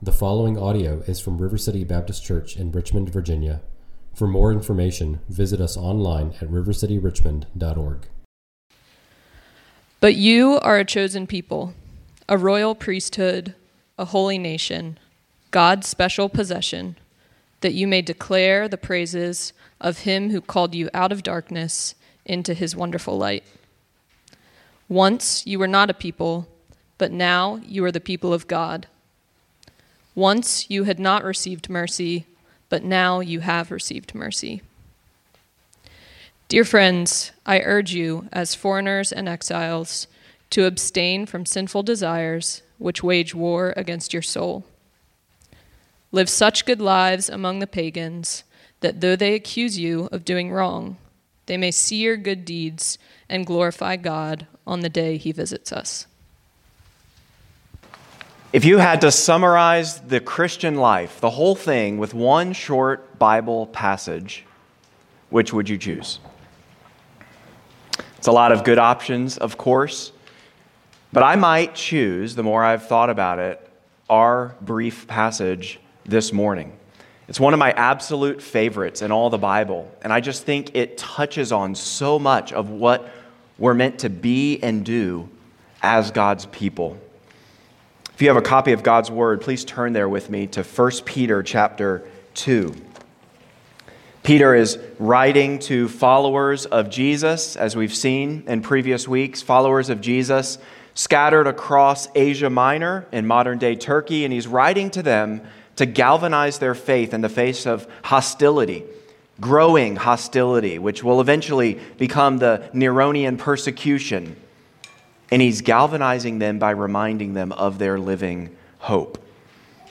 preaches 1 Peter 2:9–12 at River City Baptist Church, a new congregation in Richmond, Virginia.